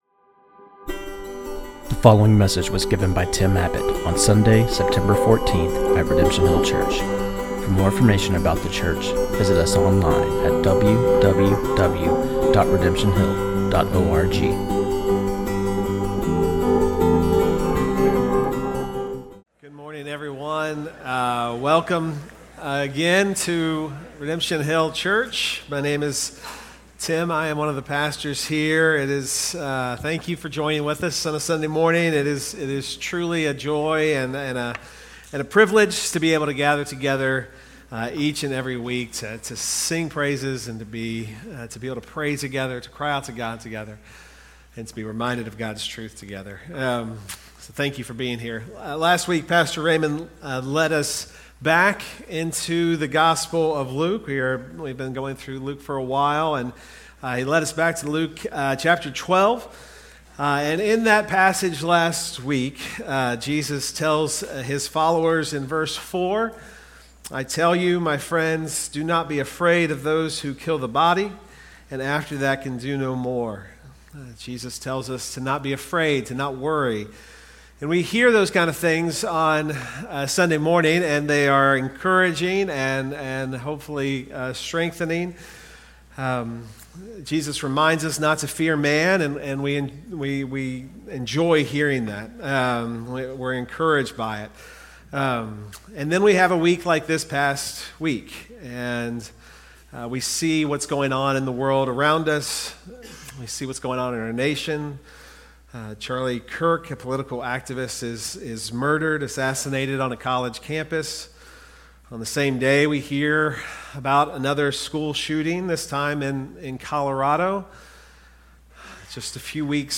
This sermon on Luke 12:13-34